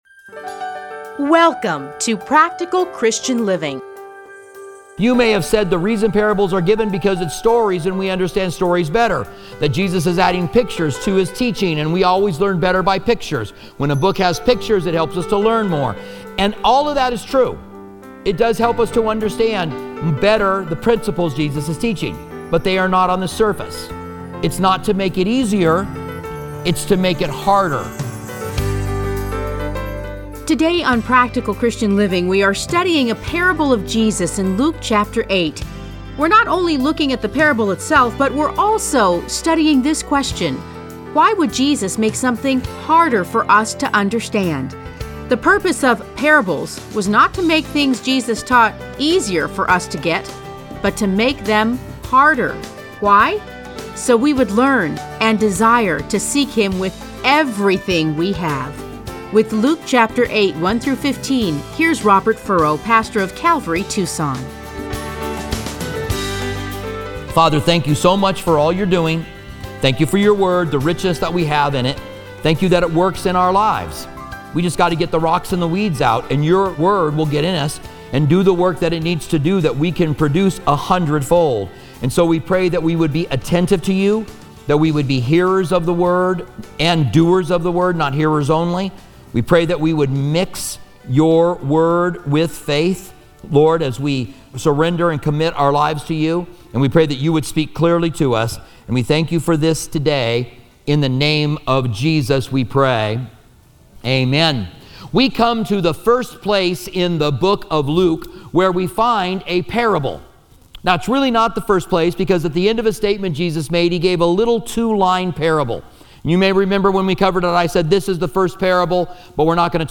Listen to a teaching from Luke 8:1-15.